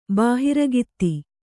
♪ bahirgata